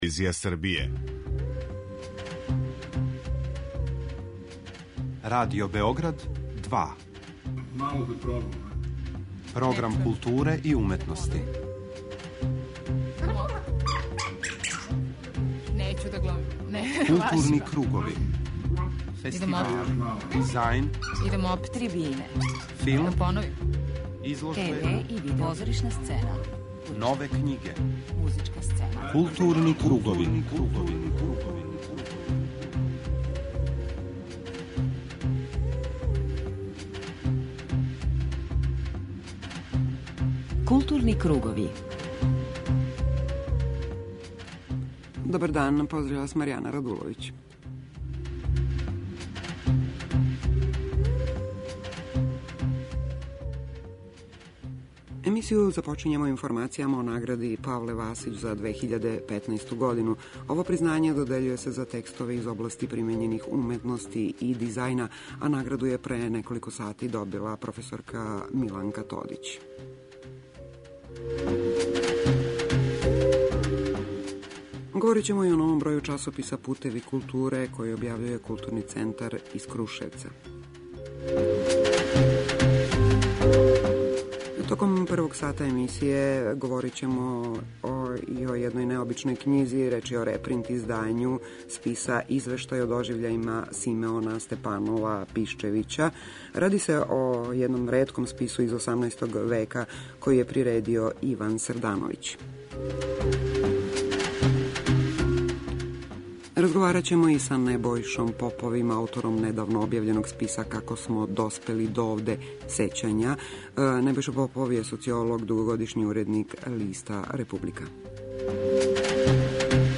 У првом делу емисије упознaћемо вас са најважнијим културним догађајима, док је други сат, као и сваког понедељка, посвећен музици.